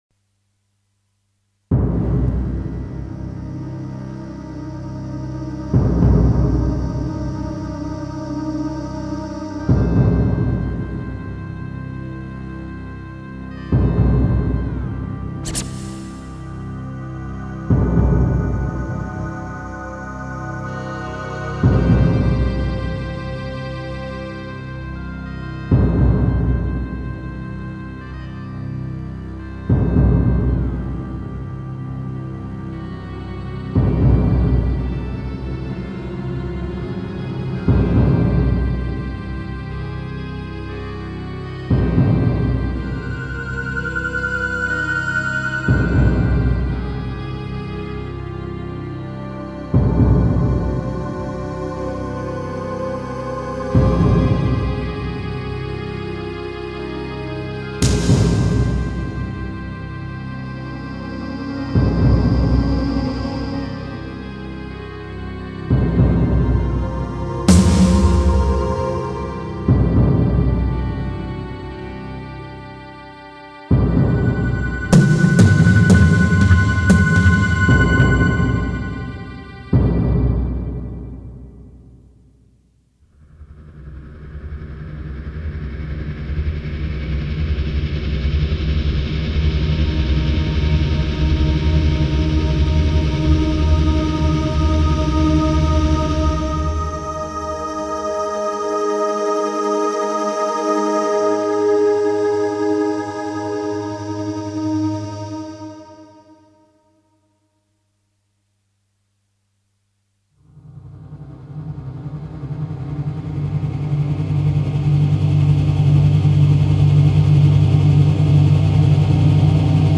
ambience_infection.mp3